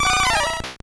Cri de Colossinge dans Pokémon Or et Argent.